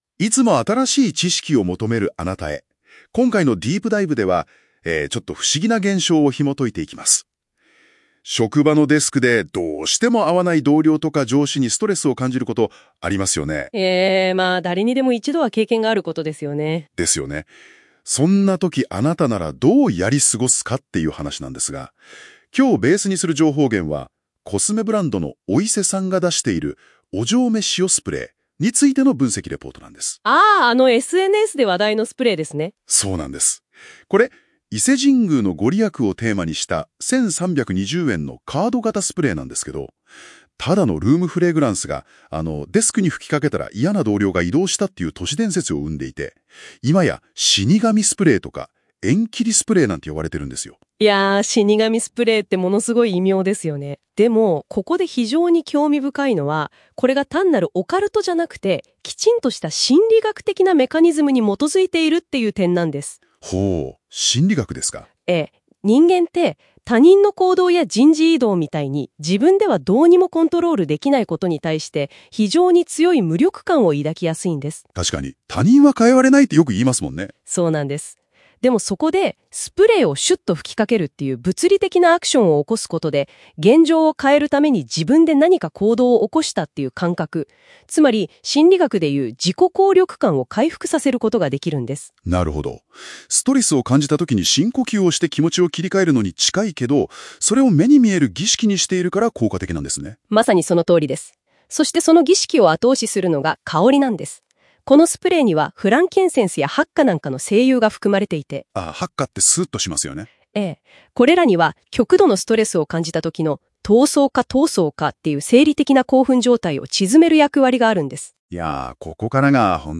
音声解説を追加しました。死神スプレー・死ねどすスプレーなんていう別名があるなんて強烈ですね。